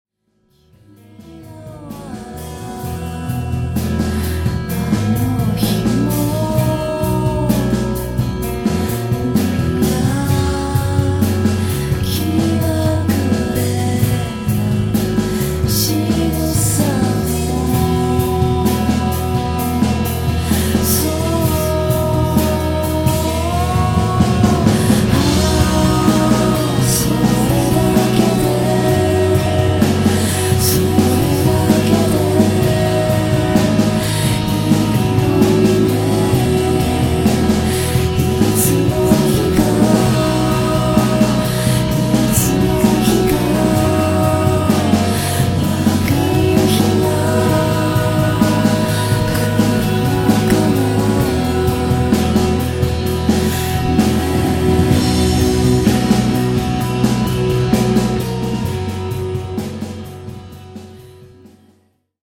極端に言葉を省略した歌詞と、音像の空間処理、そして禁欲的な歌い方が、まるでU.K.の若いバンドのようです。
でももう少しだけボーカルが聞こえた方がいいと思うけど。
サウンドはすばらしいのですが、歌のレベルが低い上に、歌い方もウィスパーがかっているので、メロディラインがあまりよくわからないし、歌詞も聞き取れません。